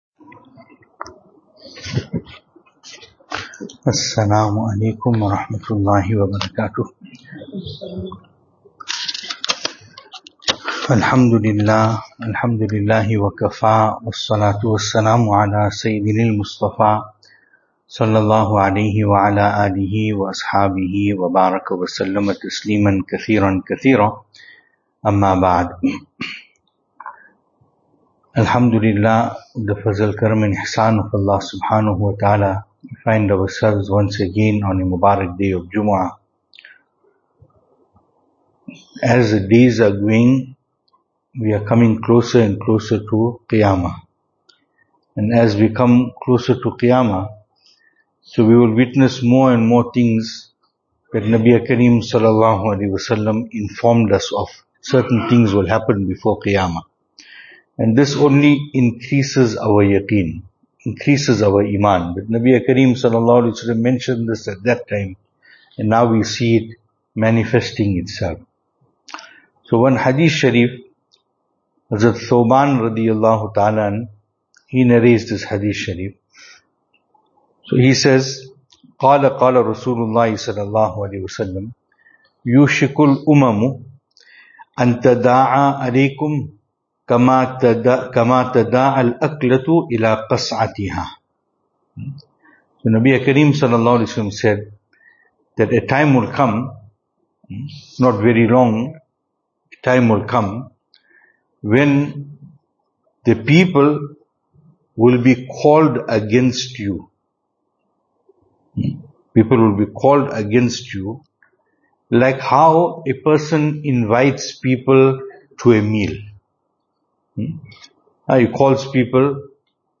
Service Type: Jumu'ah